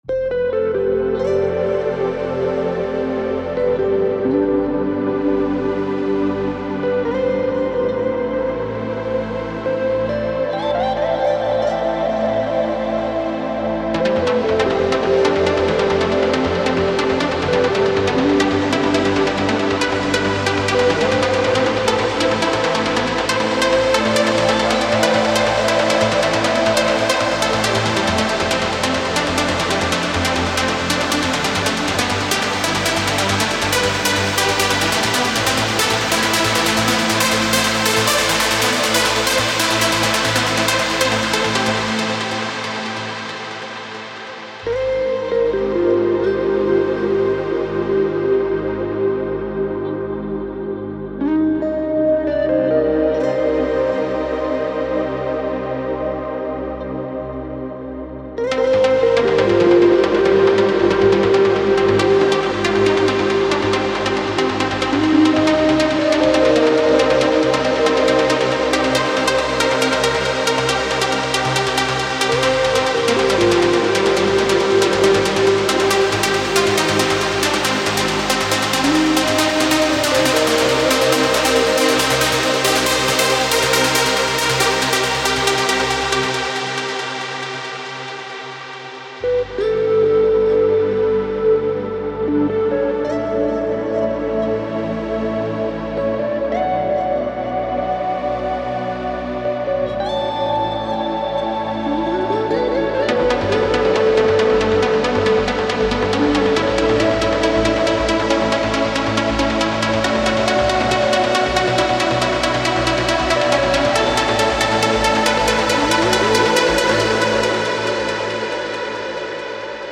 Guitar, Lead, Pad for any Kit